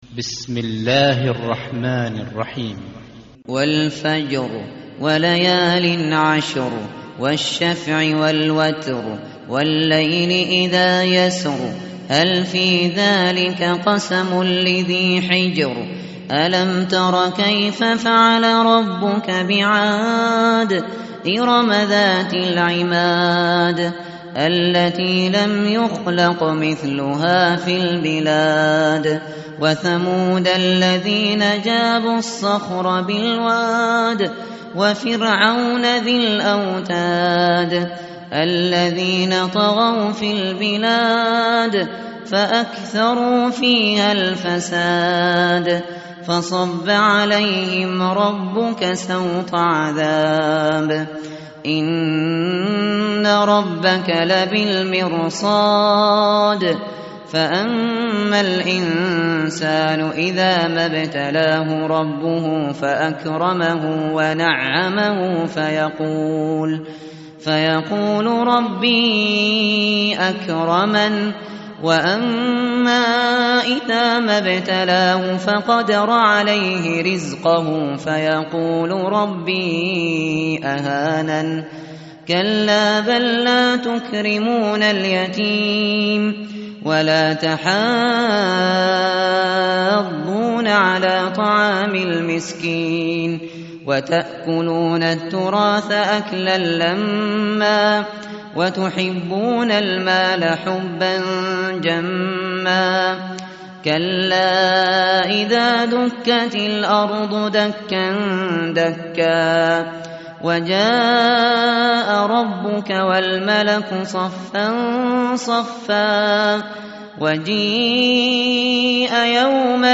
tartil_shateri_page_593.mp3